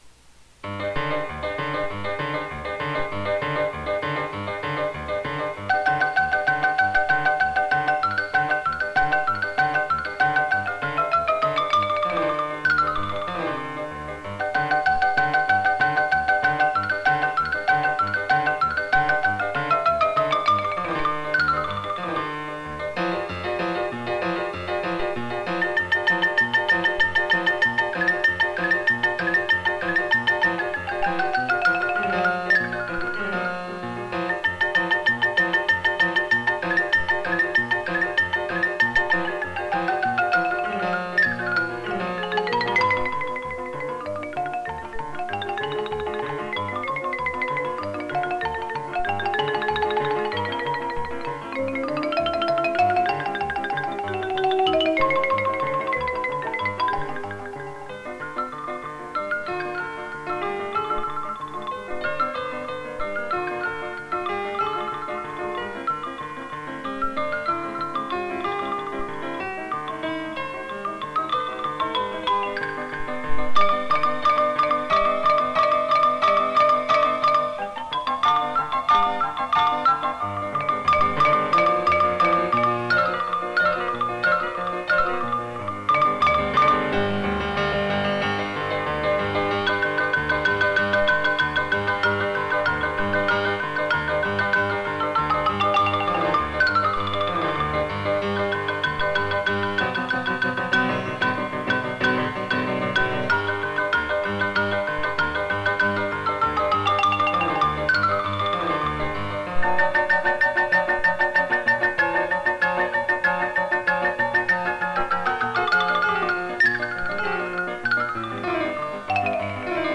マリンバ、打楽器奏者。
ピアノ伴奏版